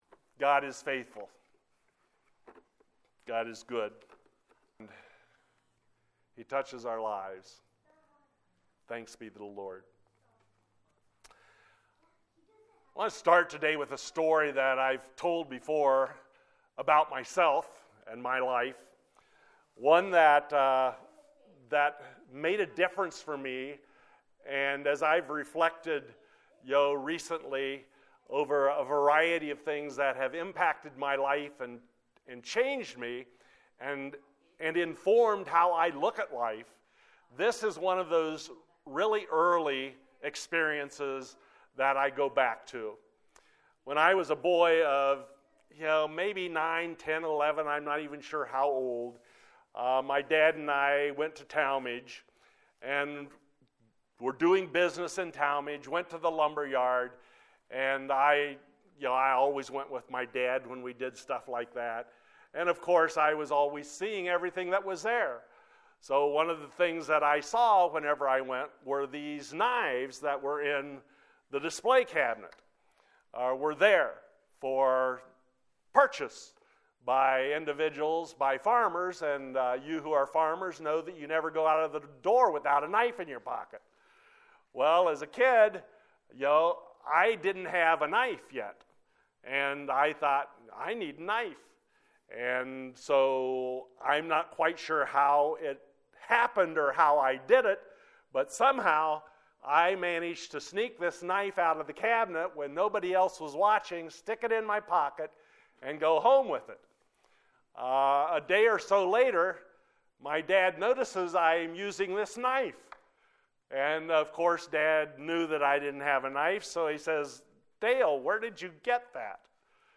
wp-content/uploads/2021/04/Being-Poor-in-Spirit.mp3 Sermon from Matthew 5:17-20.